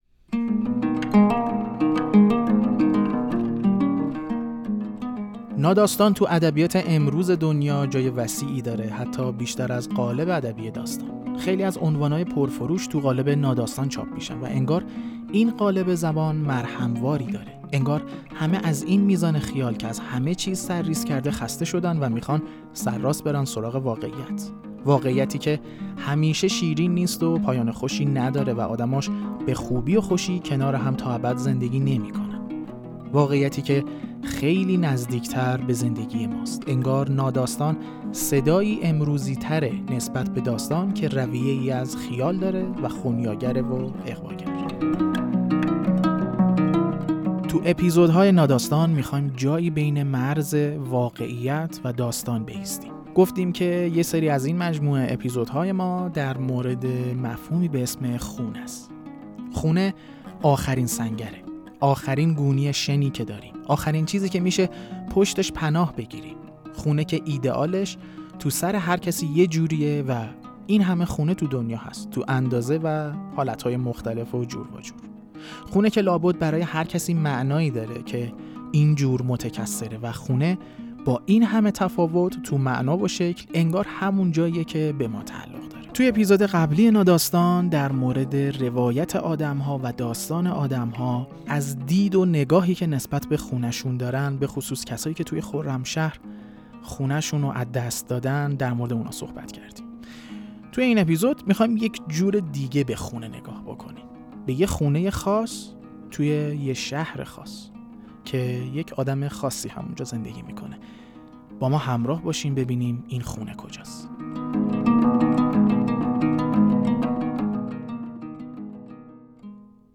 سری اول خوانش‌های ناداستان آناکست به سراغ مفهوم «خانه» رفته است.